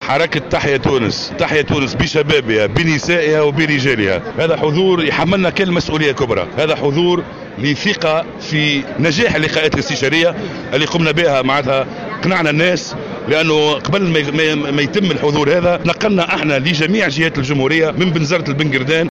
وأضاف جلاد في تصريح لمراسل الجوهرة اف ام قبل انطلاق الاجتماع الجهوي الختامي للمشروع اليوم الأحد بالمنستير، أنه سيتم خلال الاجتماع الإعلان عن موعد مؤتمره الانتخابي.